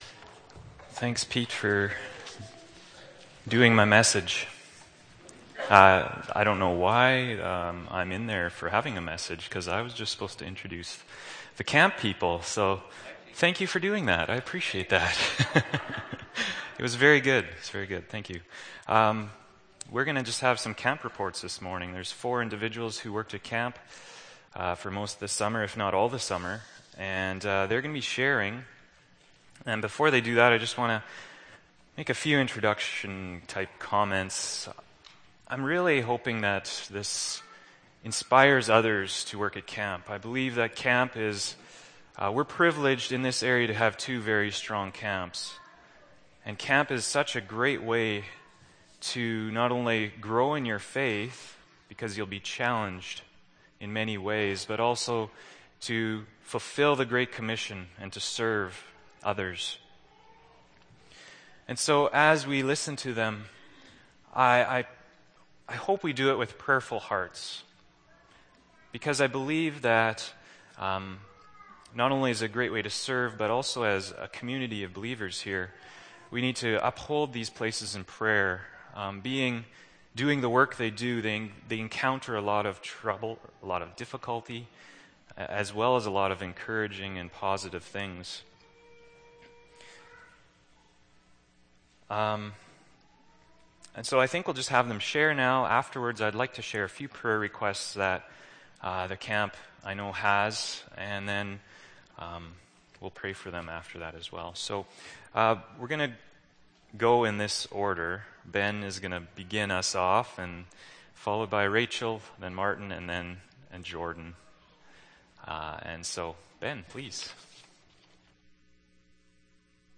April 1, 2012 – Sermon